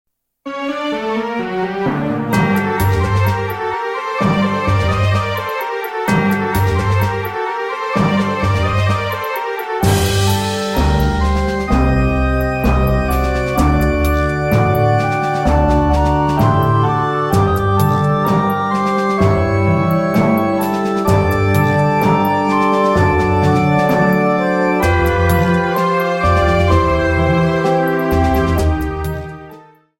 30 seconds and fadeout You cannot overwrite this file.